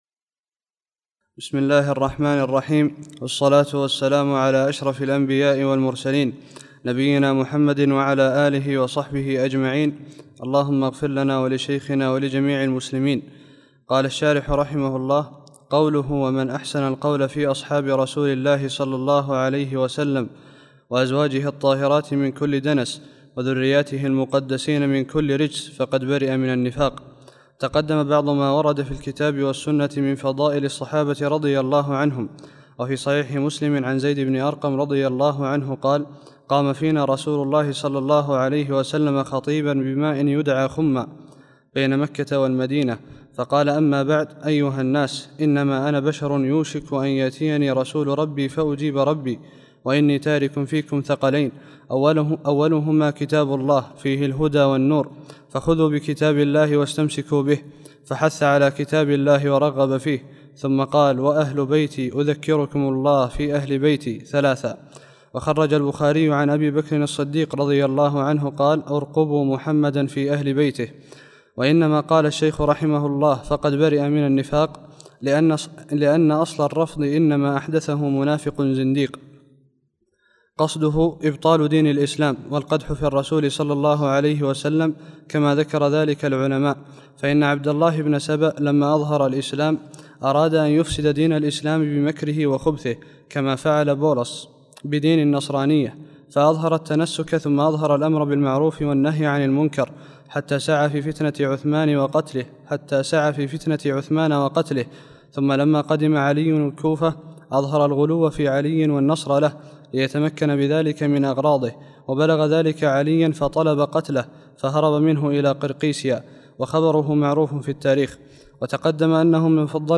53- الدرس الثالث والخمسون